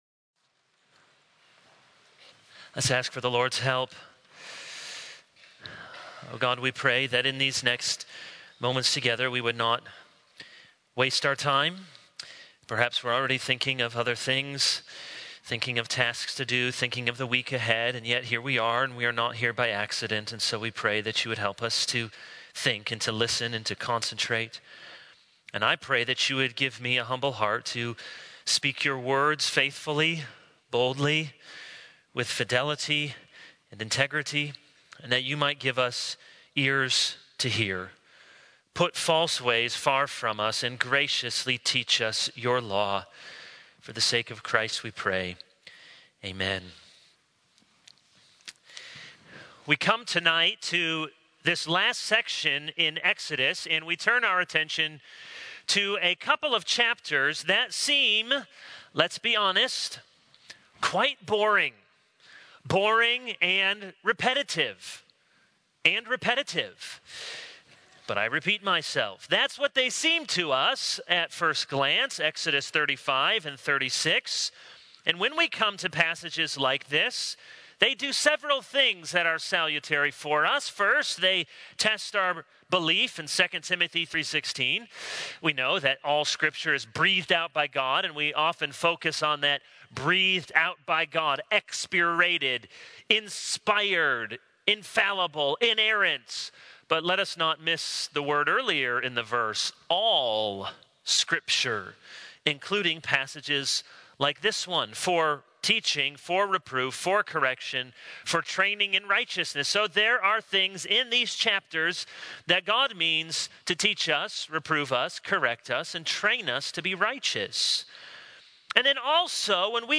This is a sermon on Exodus 35-36.